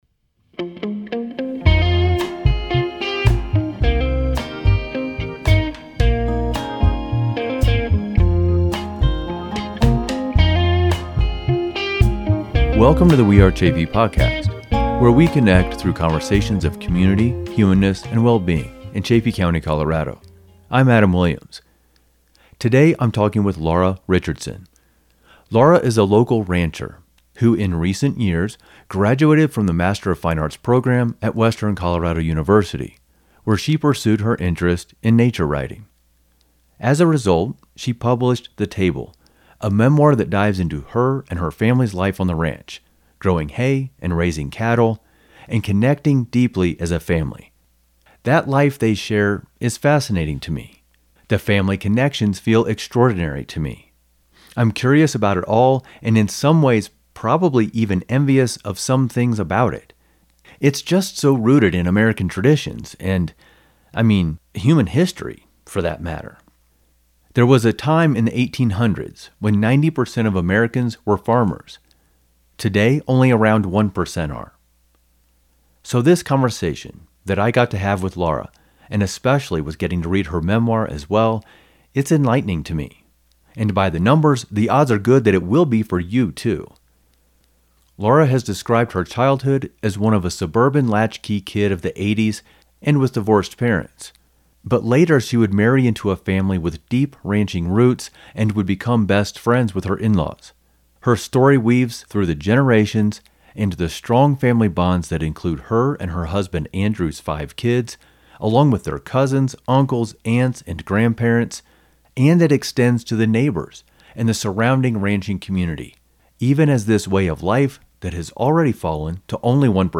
This is an incredible, full-range human conversation.